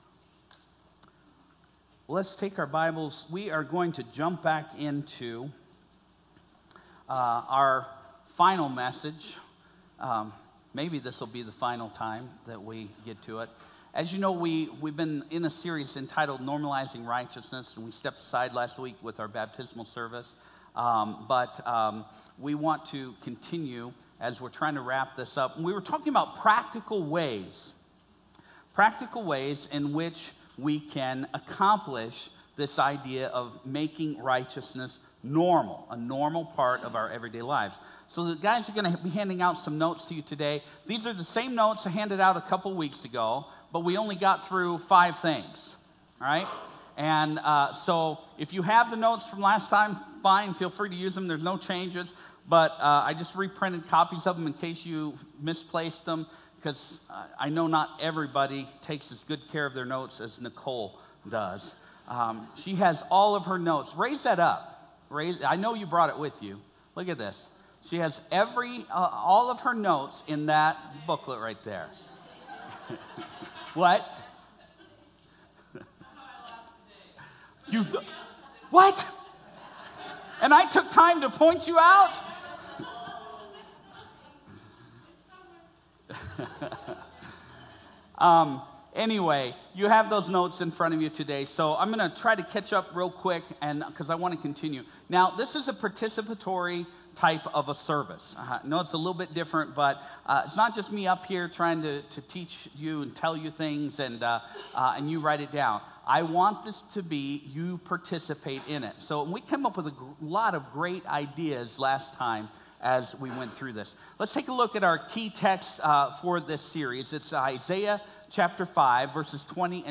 NOTE: Some portions of the recording are low due to the fact that this was an interactive service with participation from the audience.